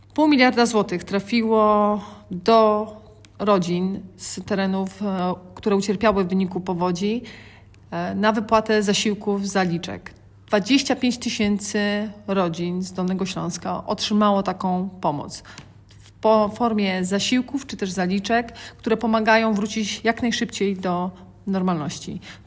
Mówi Anna Żabska – wojewoda dolnośląska.